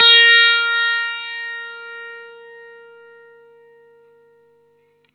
R12NOTE BF+2.wav